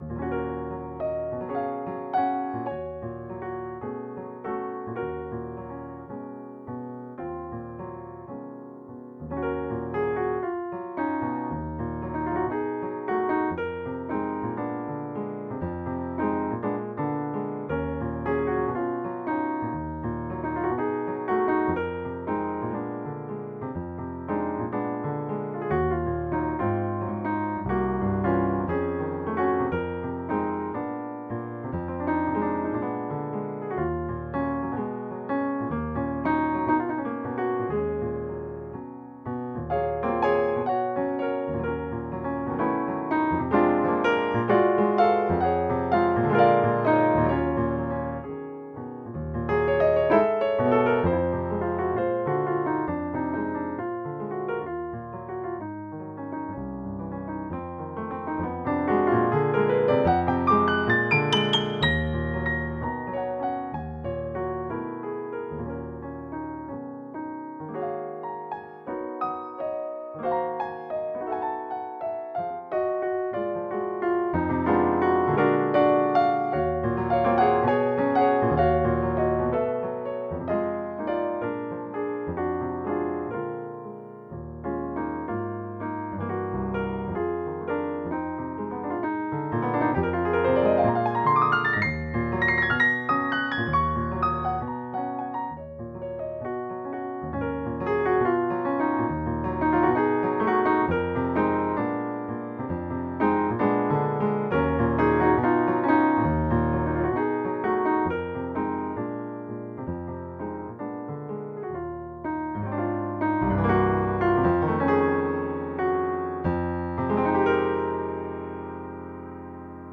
Klangproben